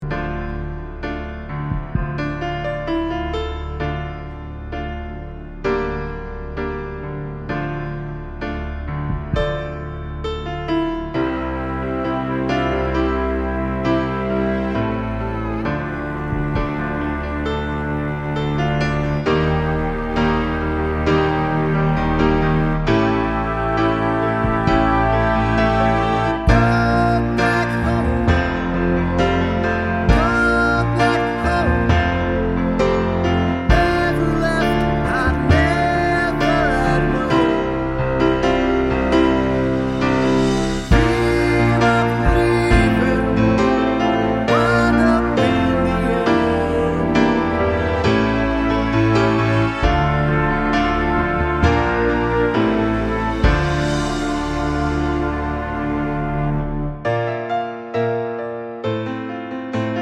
Short Version Pop (1970s) 3:29 Buy £1.50